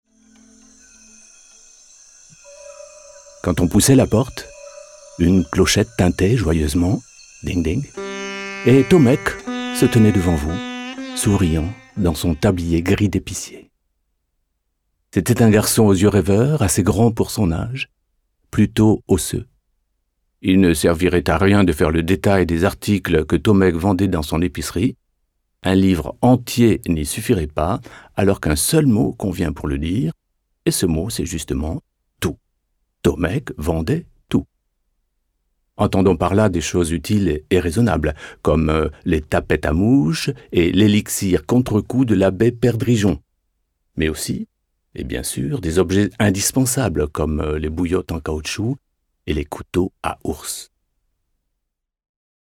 « La rivière à l’envers » de Jean-Claude Mourlevat, lu par l’auteur